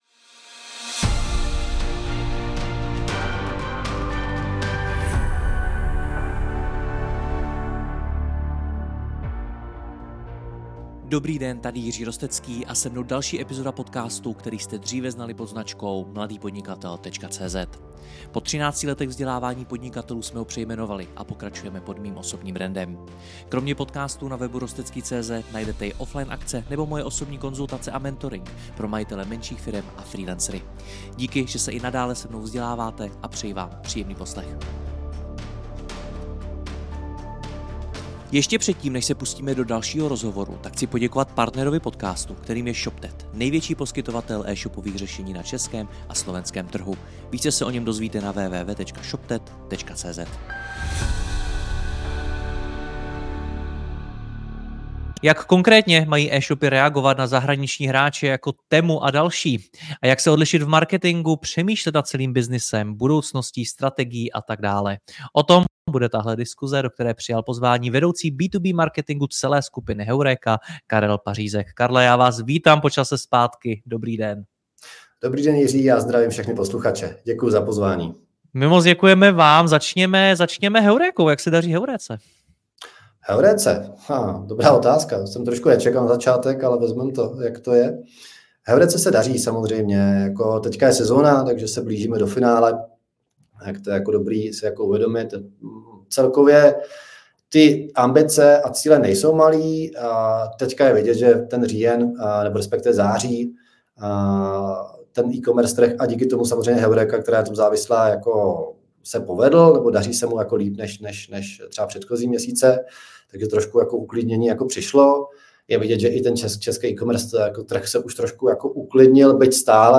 Video rozhovoru najdete zde: Toto je exkluzivní rozhovo…